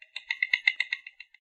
Added Vox chitter and clicking Co-authored-by
vox_click.ogg